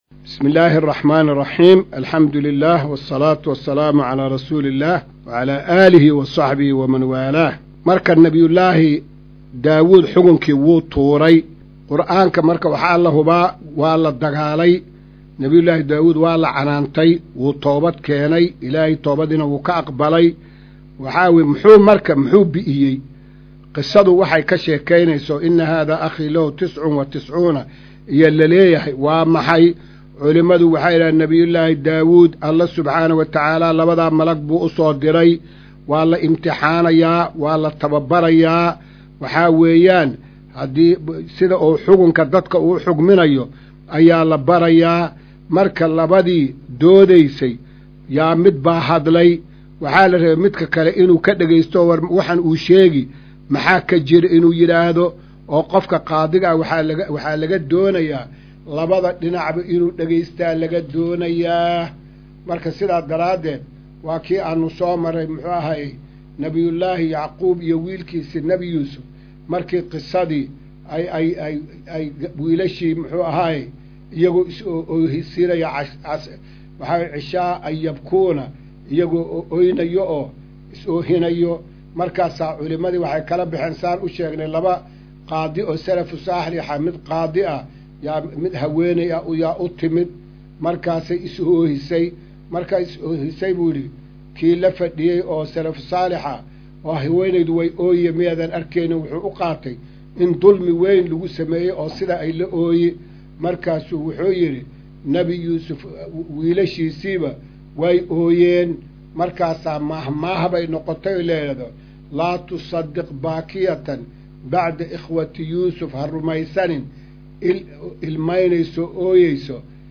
Maqal:- Casharka Tafsiirka Qur’aanka Idaacadda Himilo “Darsiga 216aad”